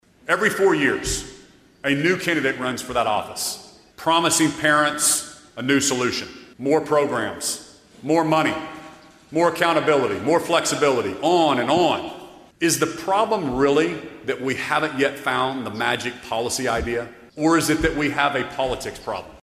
On Monday afternoon, Oklahoma Governor Kevin Stitt gave his final state of the state address.